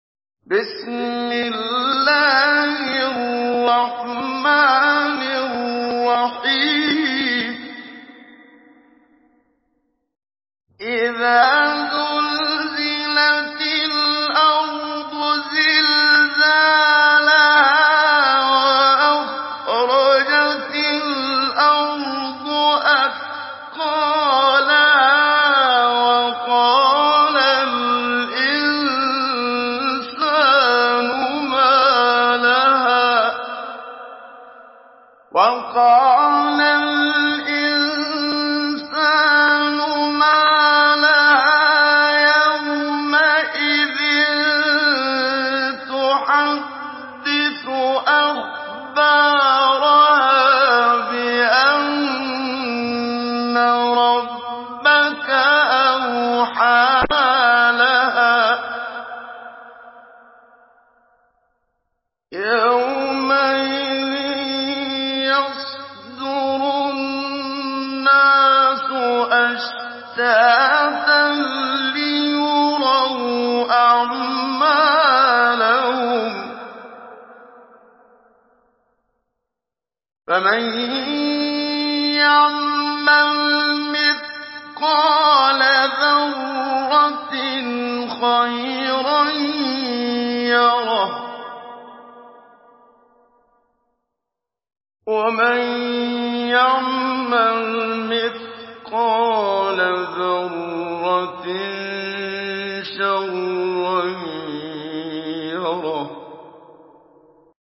Surah আয-যালযালাহ্ MP3 in the Voice of Muhammad Siddiq Minshawi Mujawwad in Hafs Narration
Surah আয-যালযালাহ্ MP3 by Muhammad Siddiq Minshawi Mujawwad in Hafs An Asim narration.